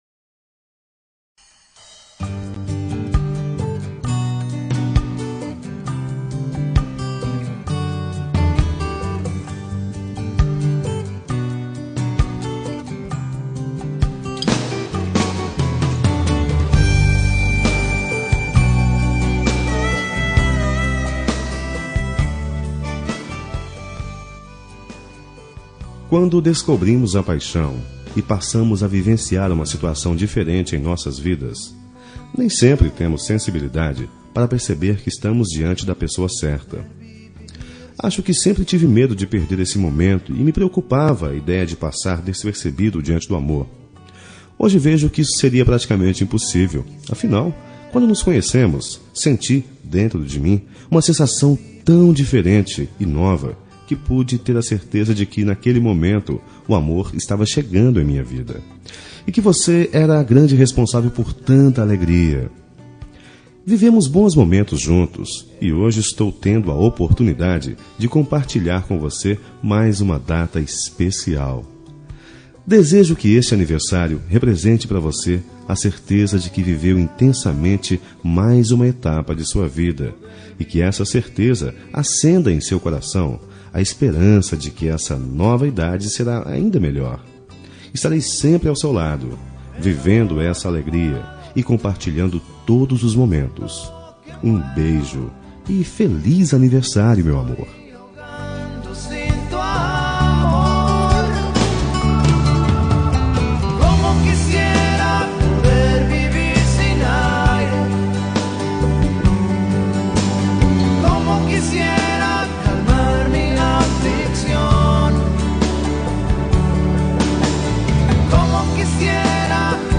Telemensagem Aniversário de Esposa – Voz Masculina – Cód: 1130